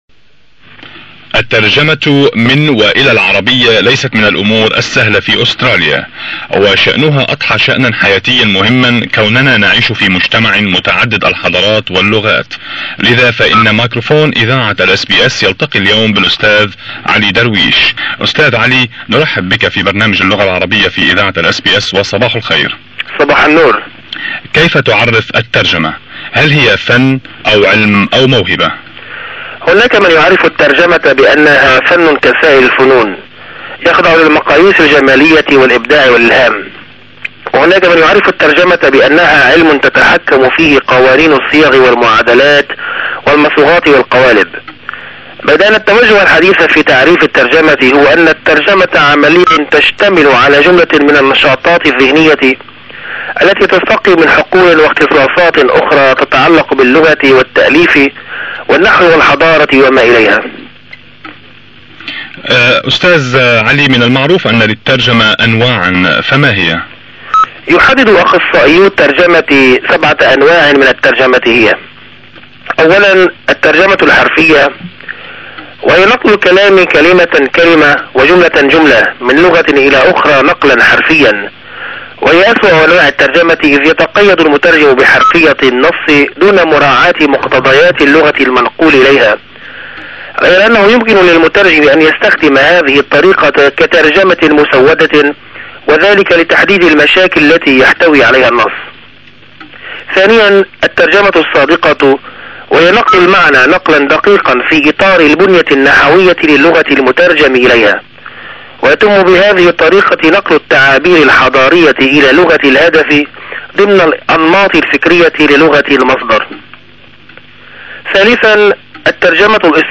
SBS Radio Interview 15 April 1993